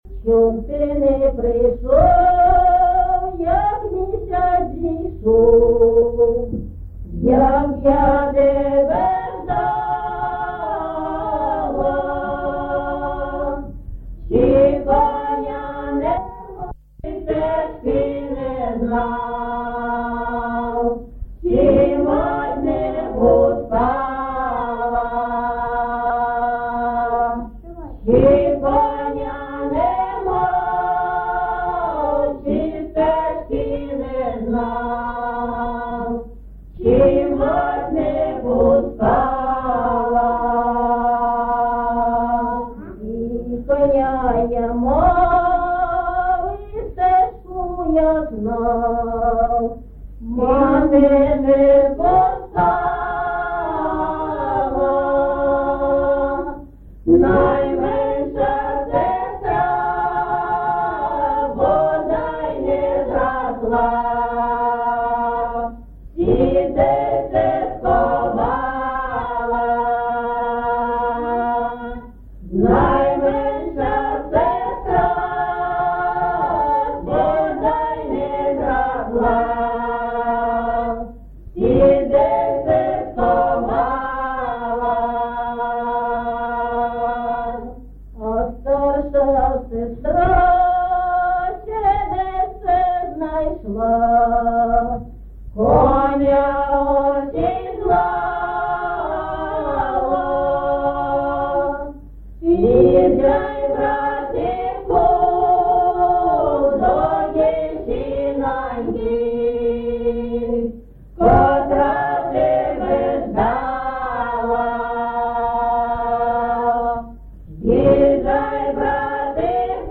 ЖанрПісні з особистого та родинного життя
Місце записум. Єнакієве, Горлівський район, Донецька обл., Україна, Слобожанщина